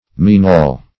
minaul - definition of minaul - synonyms, pronunciation, spelling from Free Dictionary Search Result for " minaul" : The Collaborative International Dictionary of English v.0.48: Minaul \Mi*naul"\, n. (Zool.)